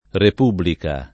republica [ rep 2 blika ]